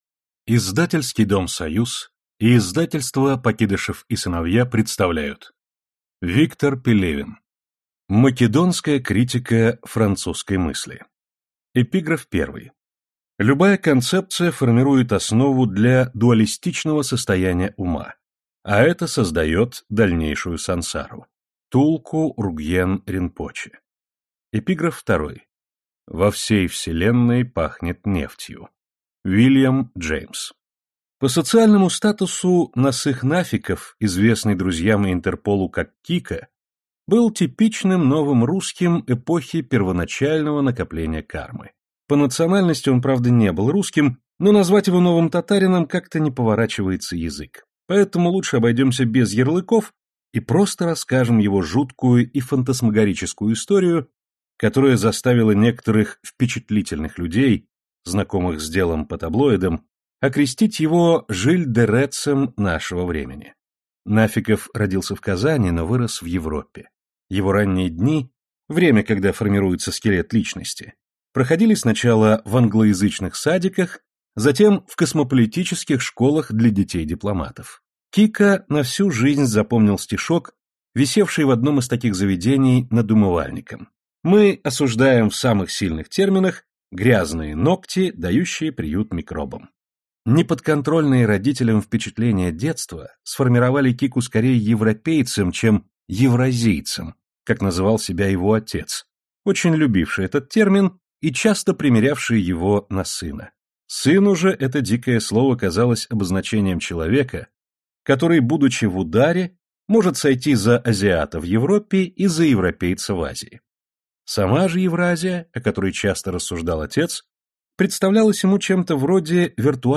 Аудиокнига Македонская критика французской мысли | Библиотека аудиокниг